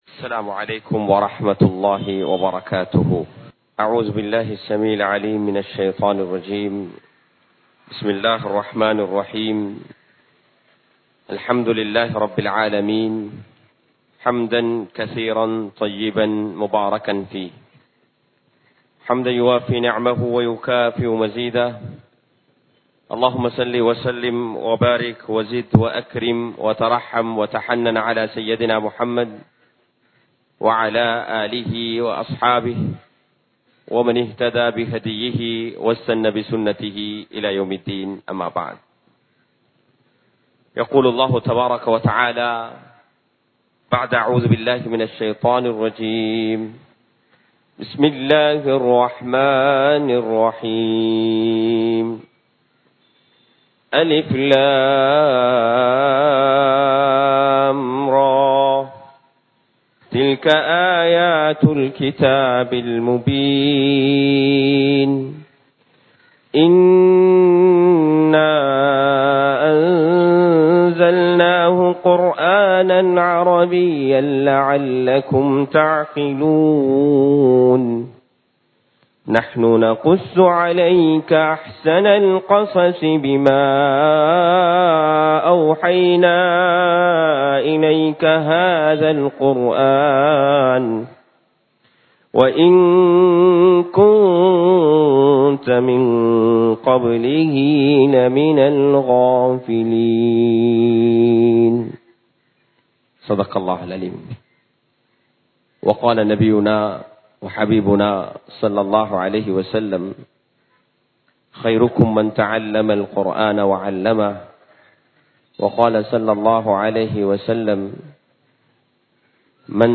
இஸ்லாம் கூறும் மனிதநேயம் | Audio Bayans | All Ceylon Muslim Youth Community | Addalaichenai